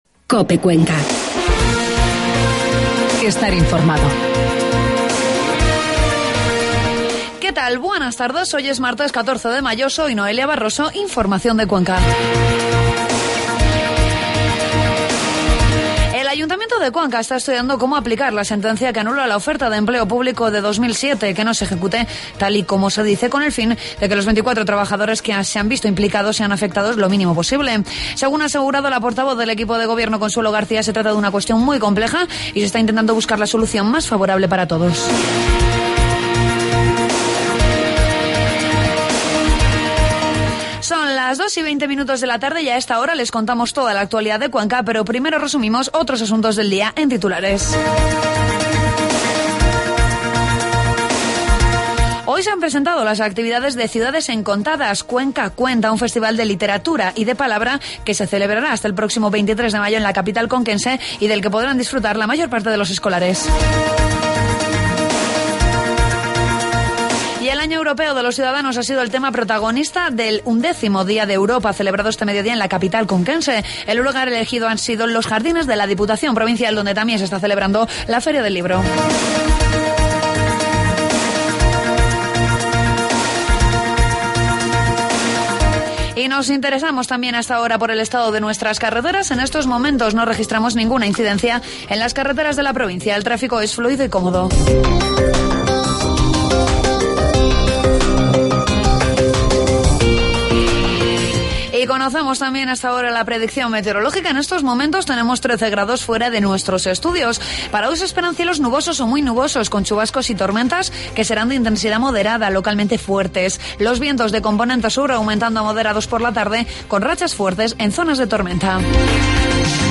AUDIO: Toda la información de la provincia de Cuenca en los informativos de mediodía de COPE